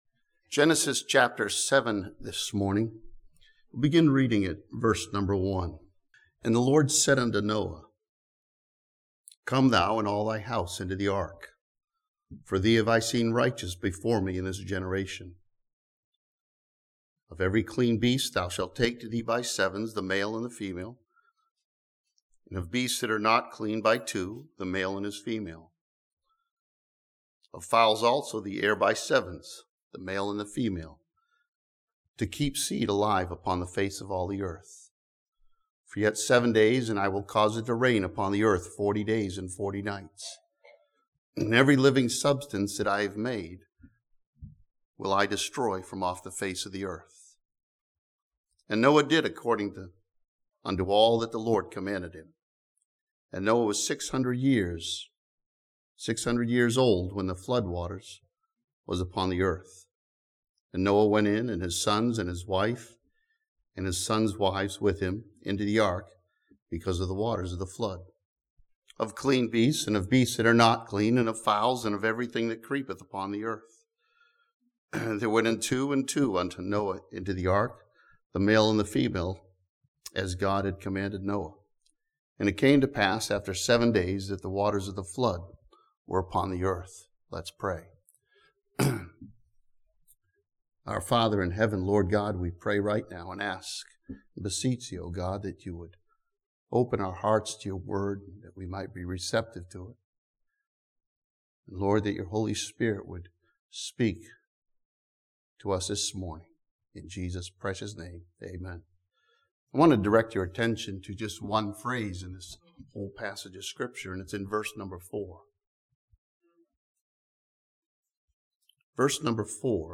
This sermon from Genesis chapter 7 studies the seven days before the worldwide flood in Noah's day.